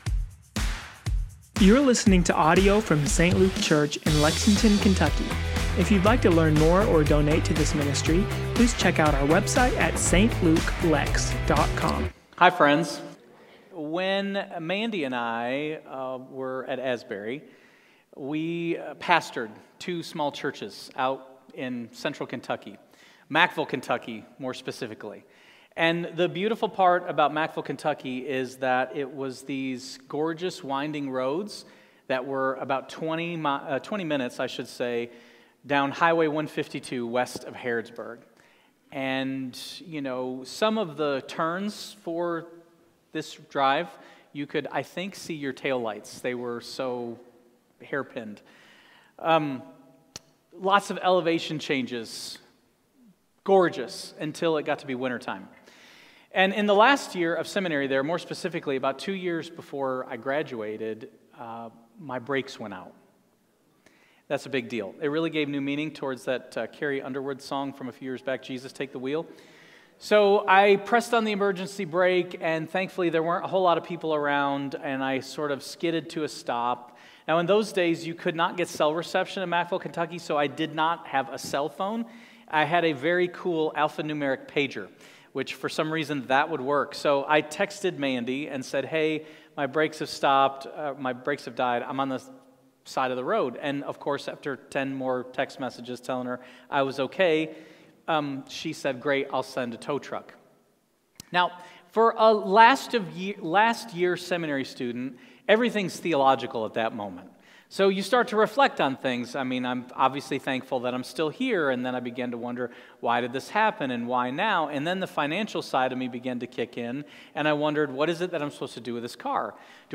Sermons & Teachings